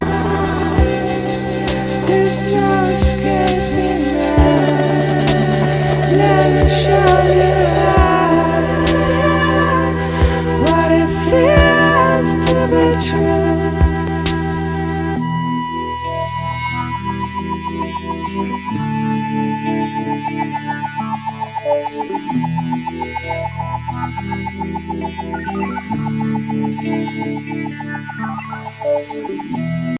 Breakbeat / Progressive House / Techno Lp Reissue